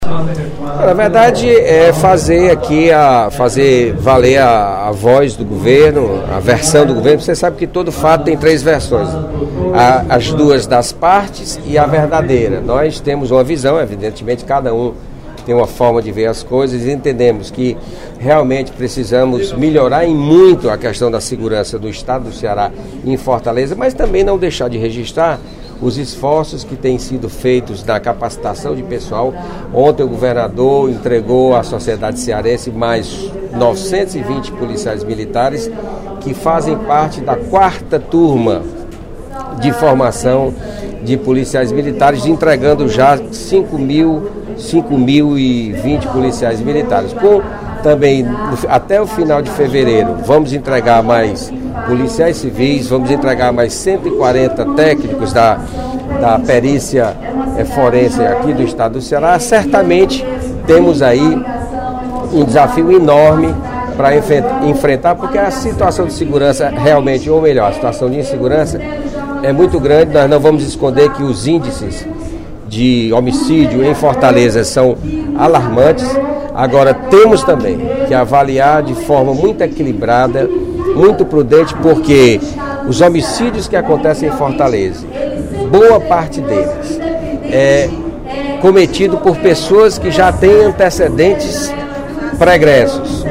Em seu primeiro pronunciamento como líder do Governo da Assembleia Legislativa, o deputado José Sarto (PSB), anunciou, na manhã desta quarta-feira (06/02), o ingresso de 920 policiais militares, oriundos da quarta turma de formação de policiais militares.